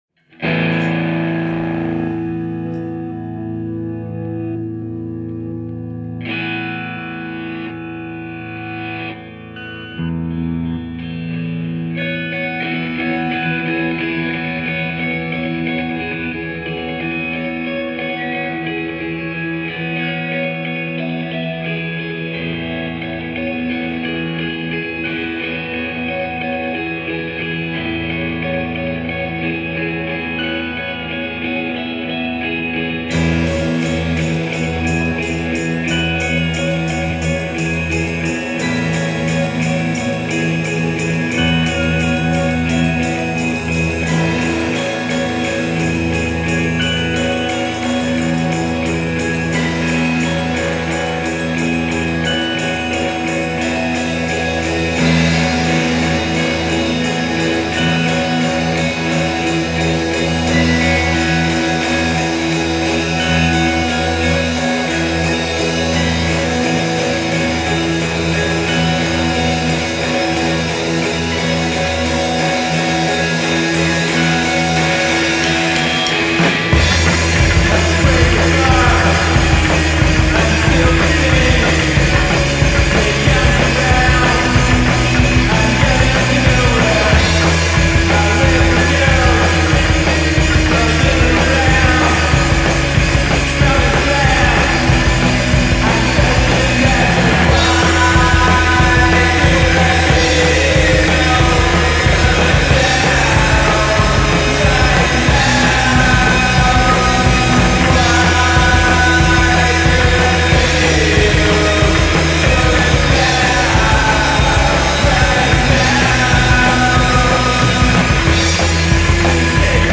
chitarra basso e batteria
una imponente e fenomenale scarica elettrica
il crescendo inarrestabile
la voce persa tra le scosse e i riverberi
Tantissima, abbagliante, micidiale elettricità.